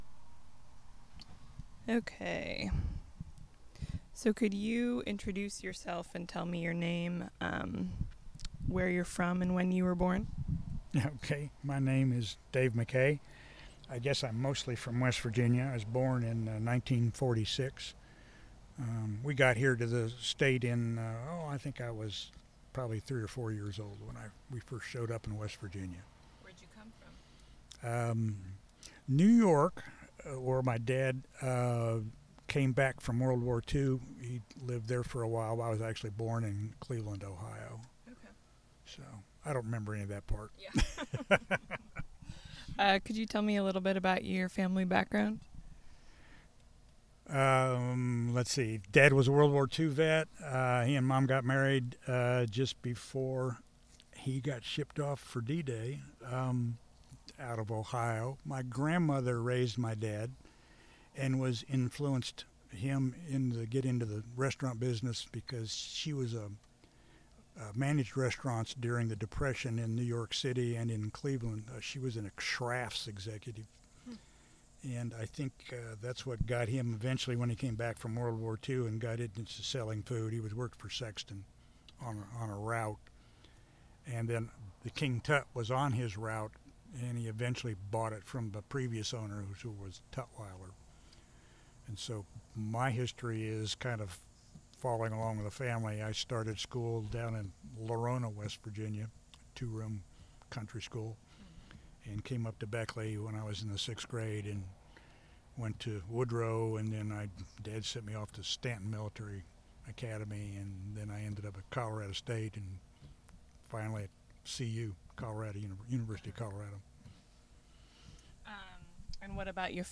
Subject: foodways , Food habits , Drive-in restaurants , Diners (Restaurants) , Restaurateurs , and Beckley (W. Va.)